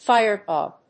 音節fíre・bùg 発音記号・読み方
/ˈfaɪɝˌbʌg(米国英語), ˈfaɪɜ:ˌbʌg(英国英語)/